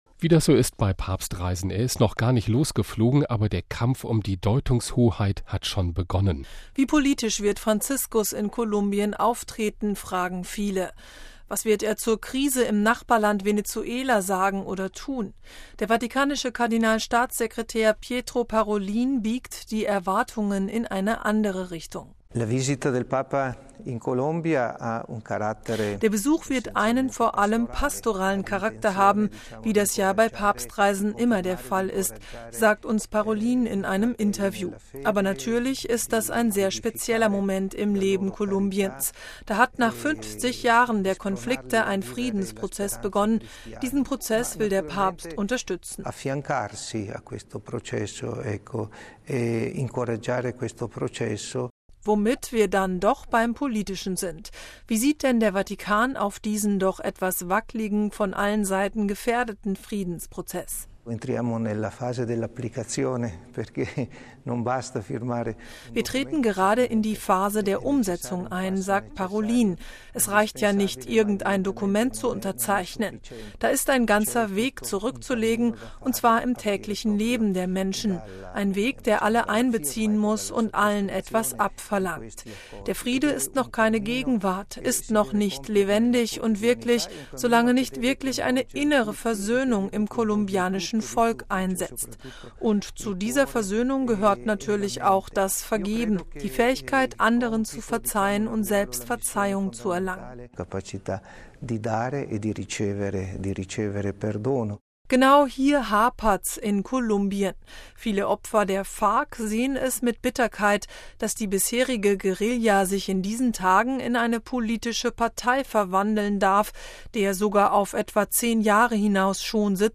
„Der Besuch wird einen vor allem pastoralen Charakter haben, wie das ja bei Papstreisen immer der Fall ist“, sagt uns Parolin in einem Interview.